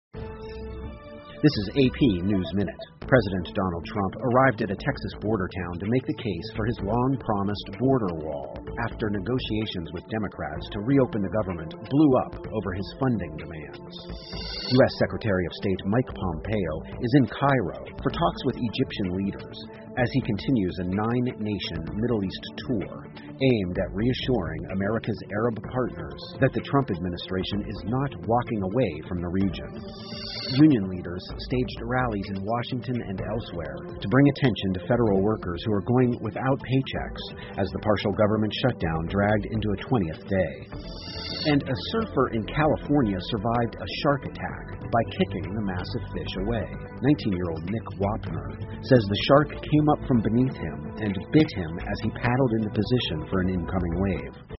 美联社新闻一分钟 AP 特朗普总统抵达德克萨斯州 听力文件下载—在线英语听力室